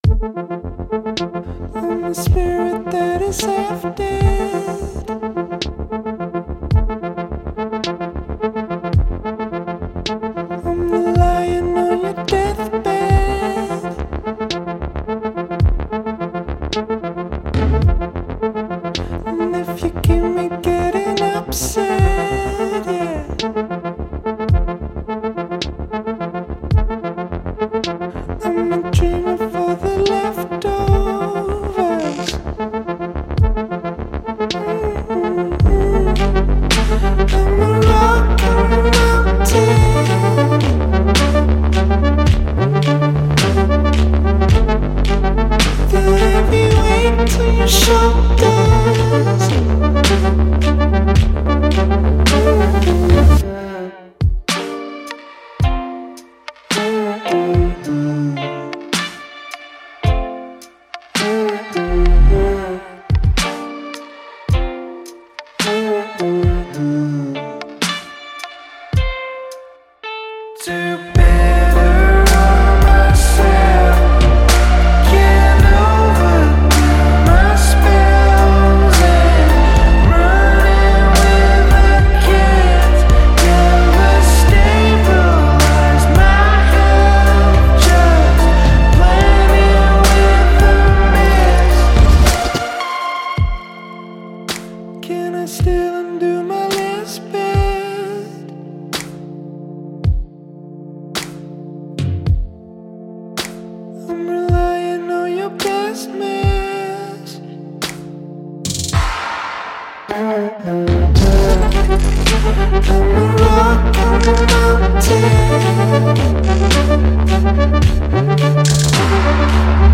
# Pop # R&B # Trip Hop # Electronic